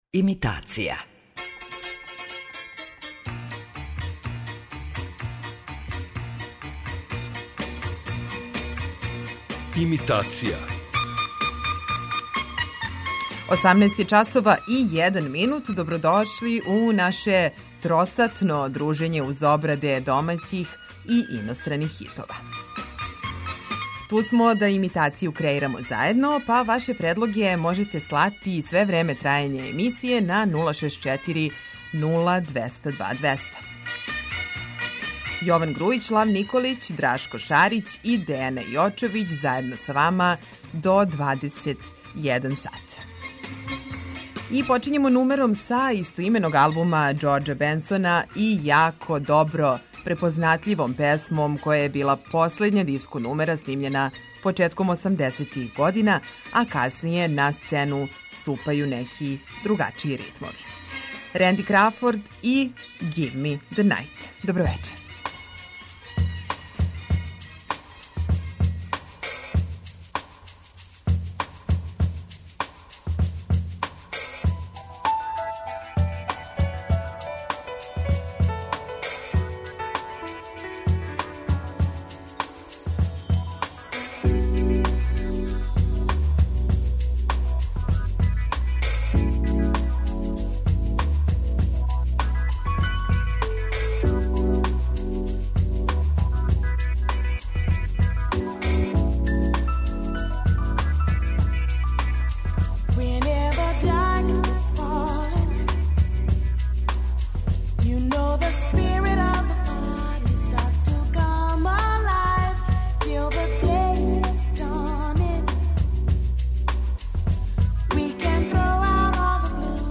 Имитација је емисија у којој емитујeмо обраде познатих хитова домаће и иностране музике.